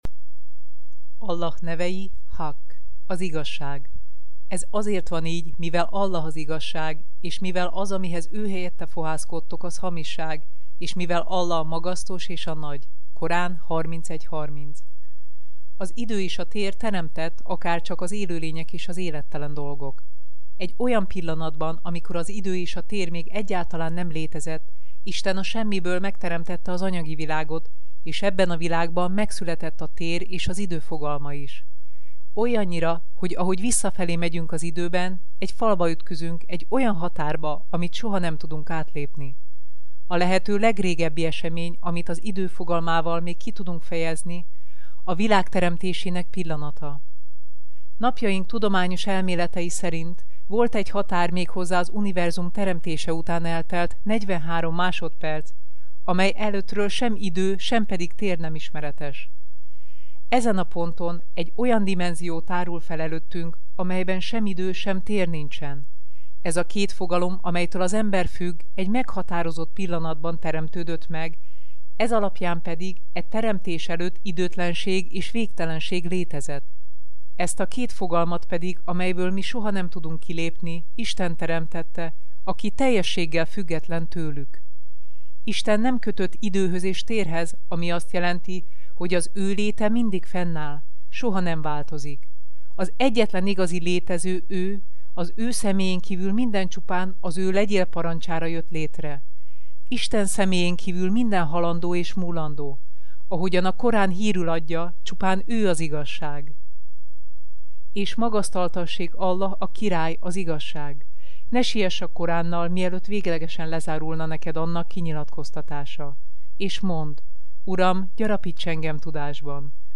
Allah nevei hangoskönyv